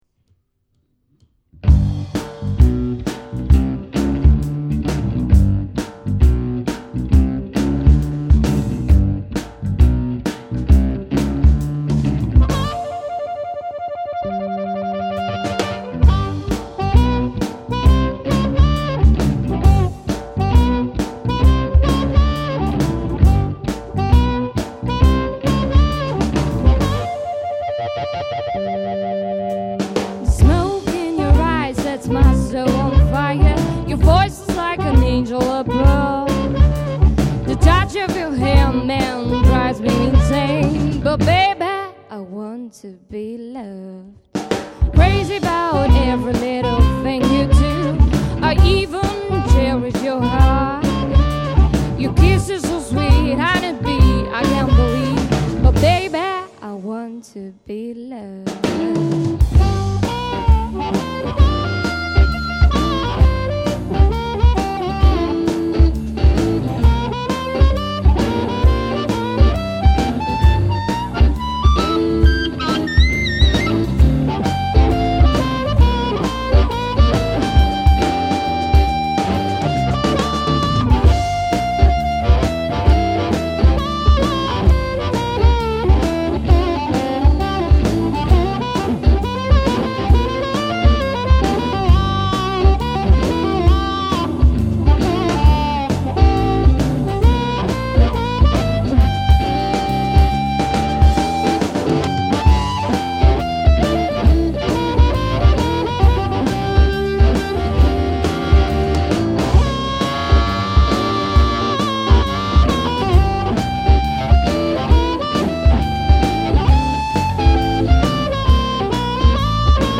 nagranie radiowe